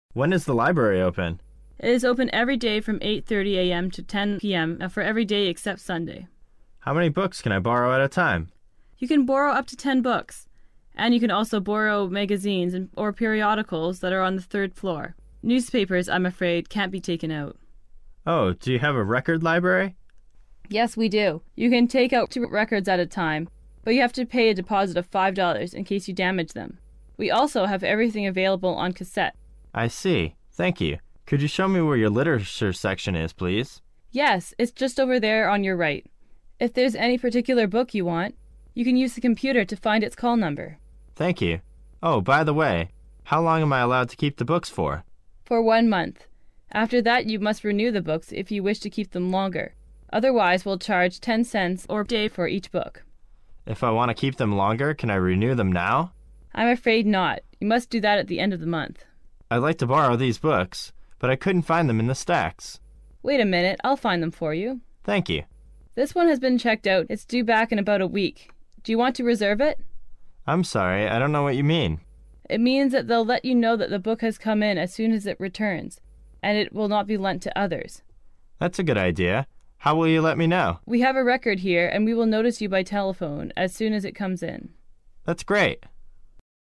英语口语900句 10.04.对话.3.初进图书馆 听力文件下载—在线英语听力室